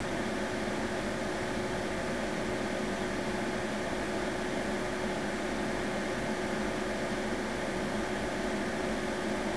The fans hummed, the drives whirred.
Nevertheless, I was determined to be at least vaguely scientific about this review, so I pulled out the omnidirectional microphone I had borrowed from the media unit at work and started making recordings.
Before I had applied the Dynamat Extreme Computer Kit, I had taken three 10 second recordings: one at the front left of the machine, one at the front right, and one at the direct rear.